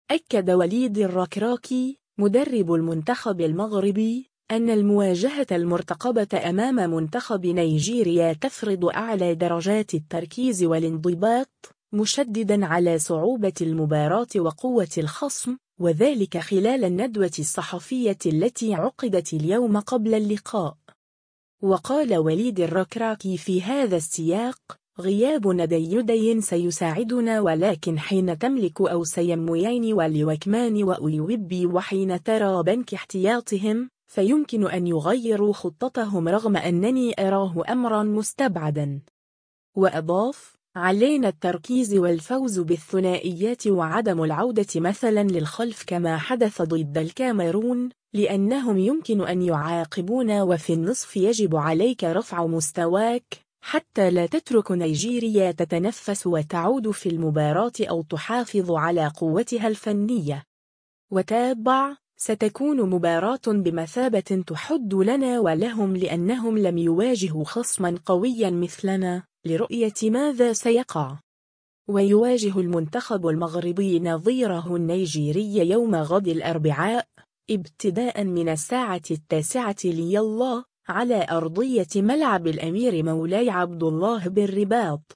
أكد وليد الركراكي، مدرب المنتخب المغربي، أن المواجهة المرتقبة أمام منتخب نيجيريا تفرض أعلى درجات التركيز والانضباط، مشددًا على صعوبة المباراة وقوة الخصم، وذلك خلال الندوة الصحفية التي عقدت اليوم قبل اللقاء.